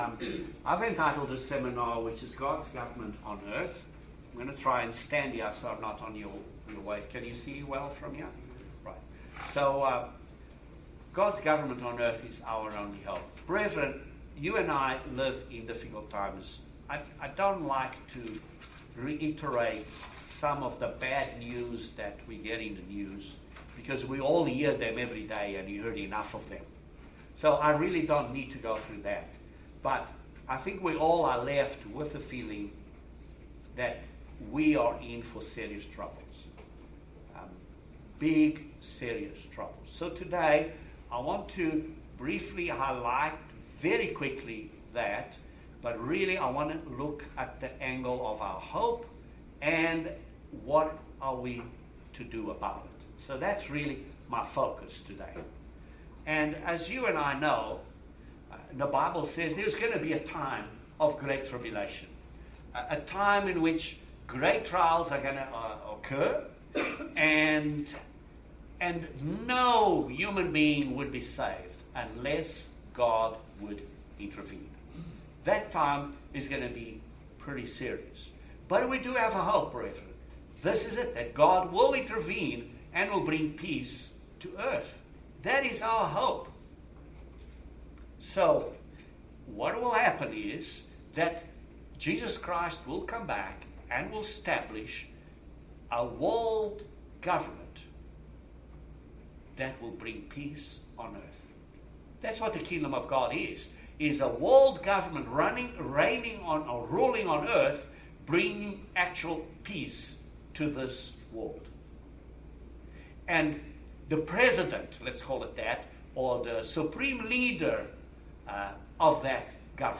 Join us for this Excellent Kingdom of God seminar sermon on the soon coming Kingdom of God.